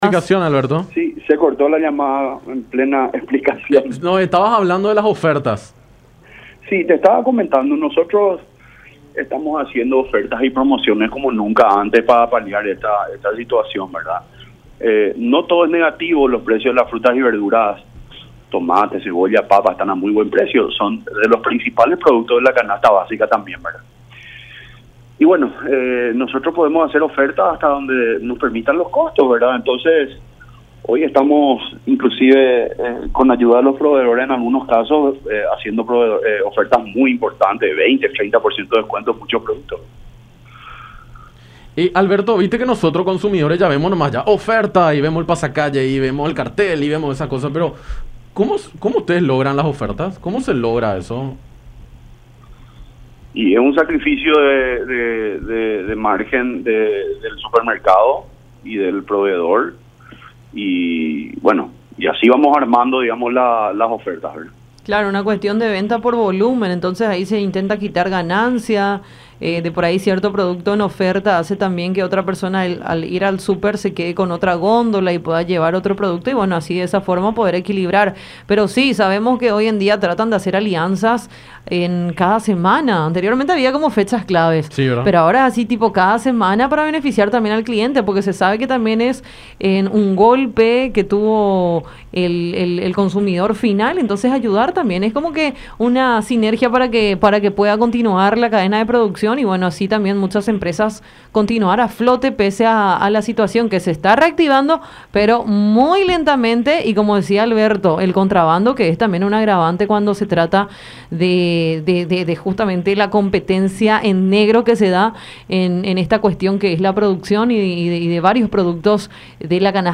en diálogo con Enfoque 800 por La Unión.